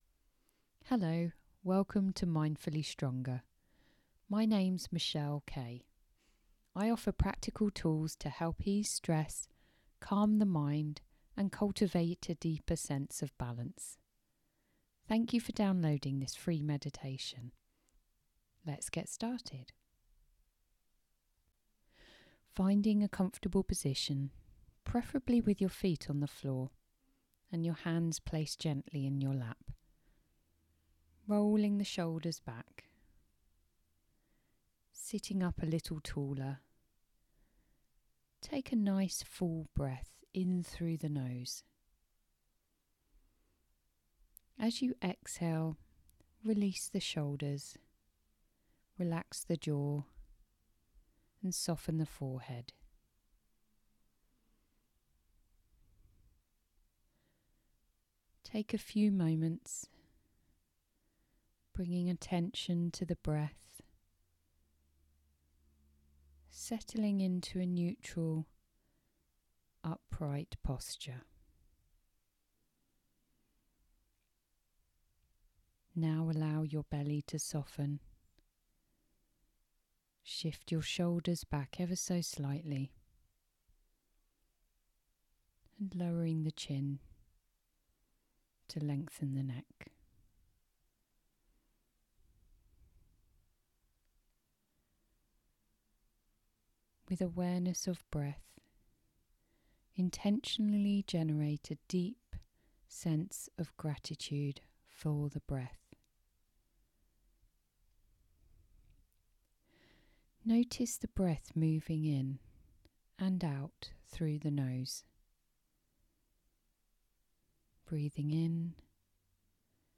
This free guided meditation is a gentle introduction to mindfulness. The audio is approximately 10 minutes long and offers a calm, grounding pause for moments when you are feeling overwhelmed or curious about mindfulness.
6-mins-Gratitude-Meditation.mp3